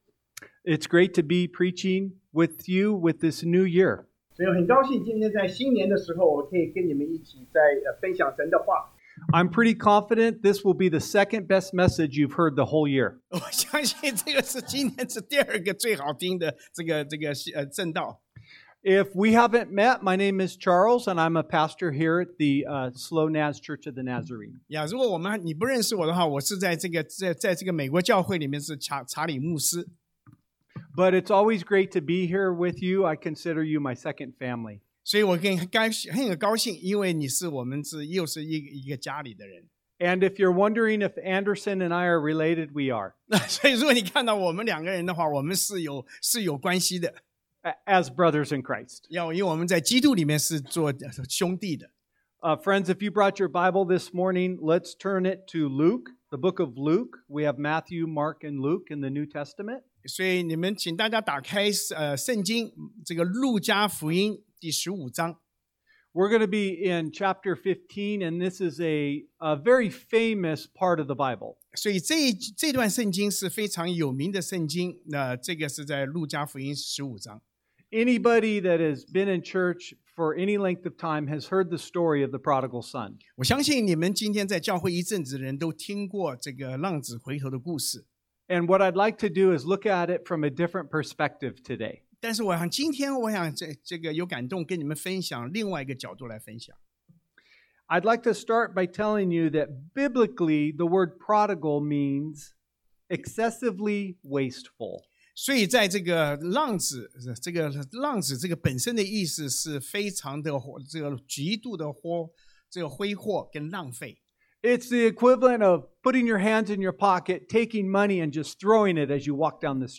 路加福音 Luke 15:11-32 Service Type: Sunday AM 第一部分：什麽是浪子？ | What is the prodigal?